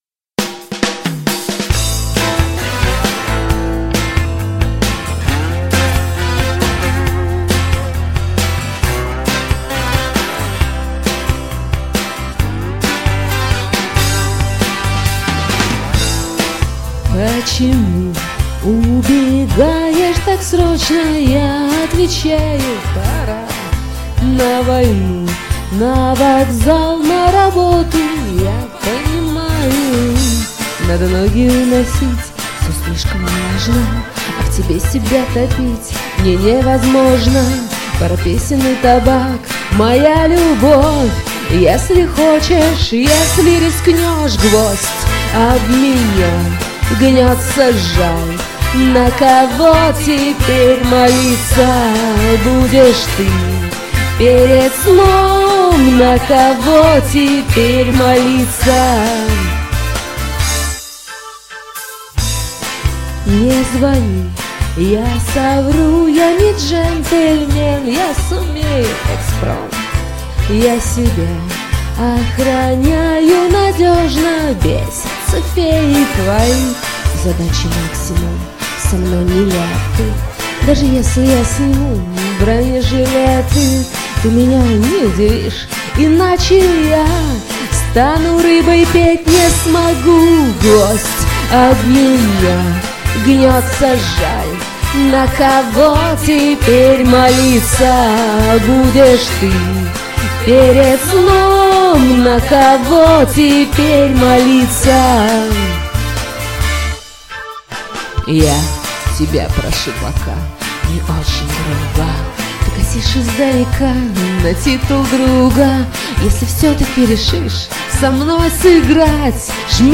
прошу прощения за каКЧИство записи...